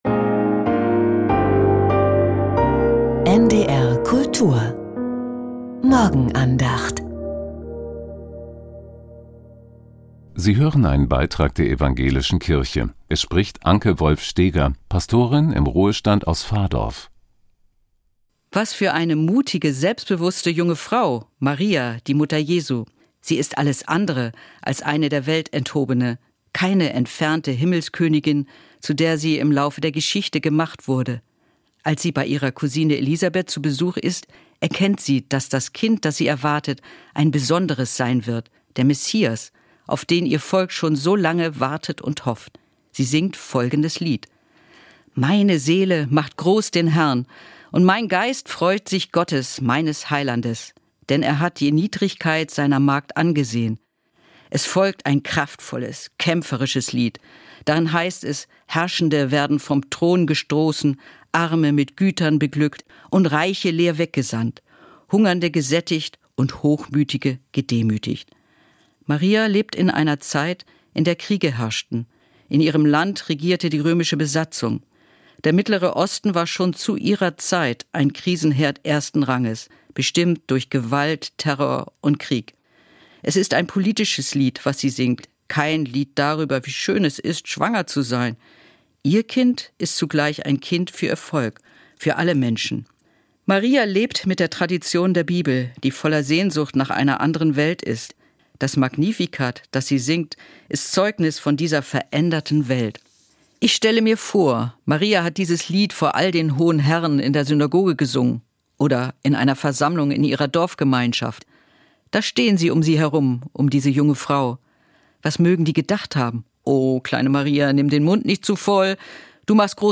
Magnifikat ~ Die Morgenandacht bei NDR Kultur Podcast